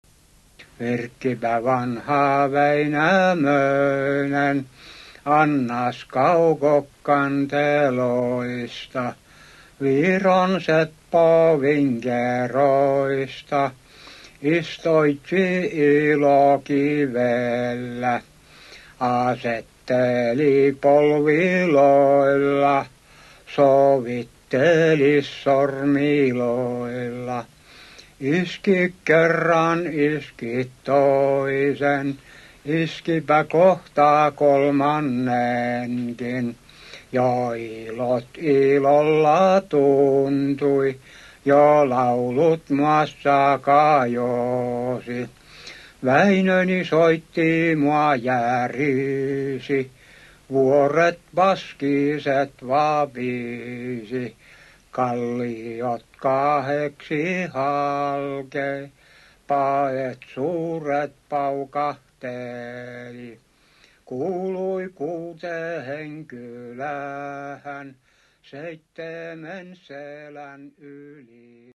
Runonlaulua isältä pojalle, soittimena ilovehe
kalevalamittaisen runon Väinämöisen kanteleensoitto
1966 Helsingissä